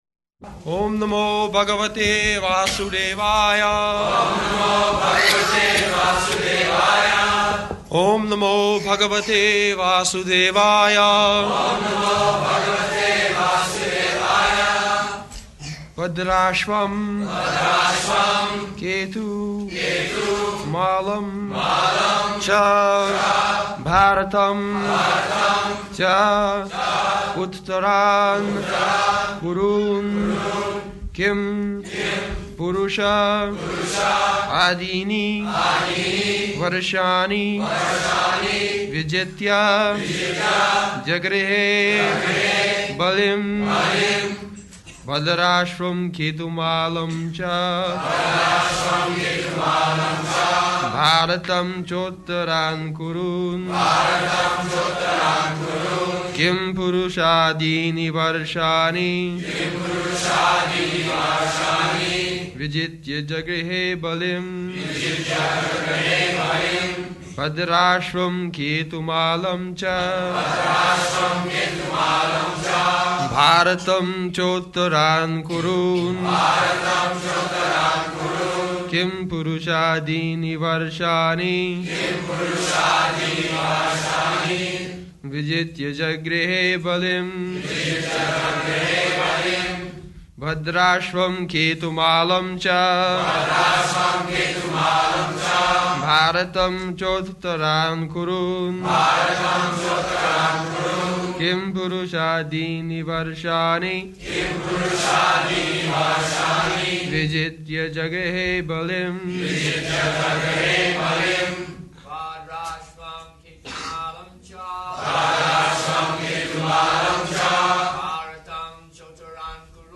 January 9th 1974 Location: Los Angeles Audio file